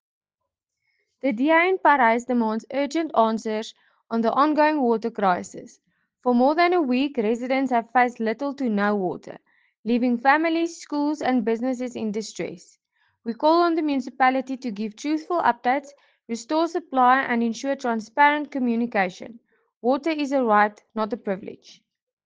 Afrikaans soundbites by Cllr Marié la Cock.